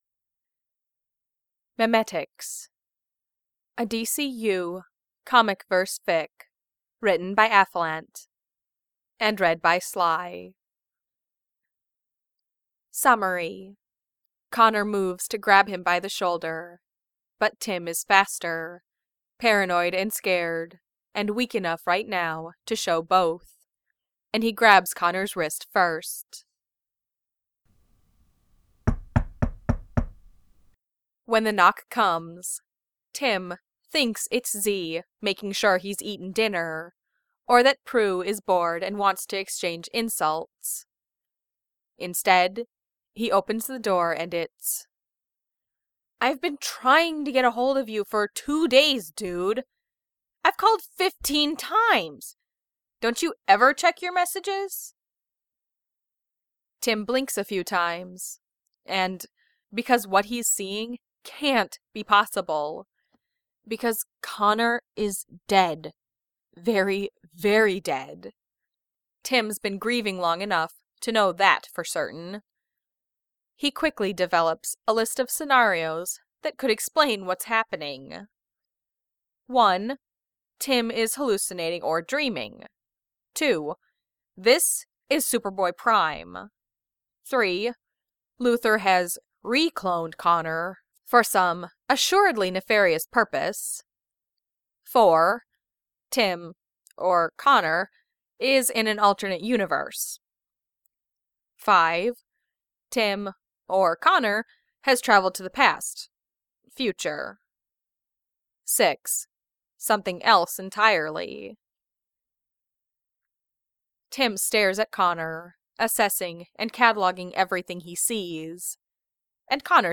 MP3 (5.2 MBs) |-|-| Audiobook (3.27 MBs) (Right click and "Save As")